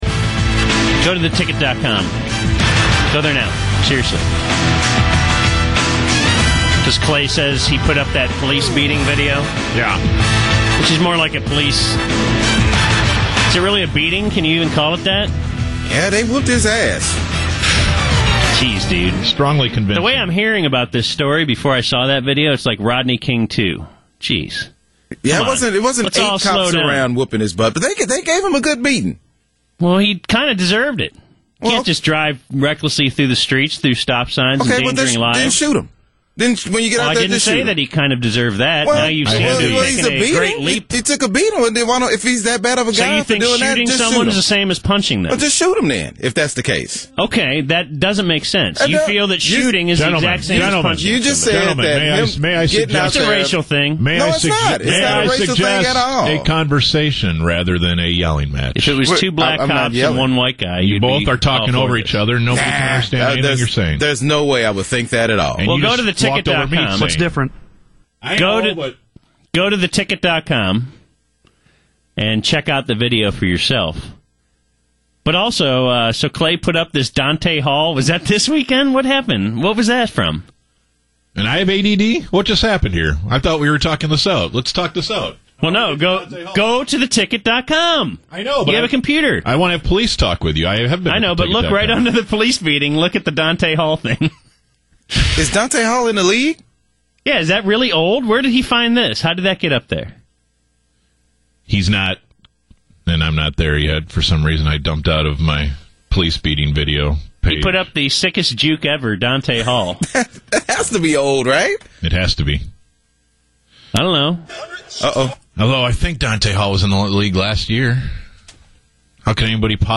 BaD Radio opened yesterday’s show with a very spirited argument about this incident that may or may not have left me shivering under the table and clutching my blankey.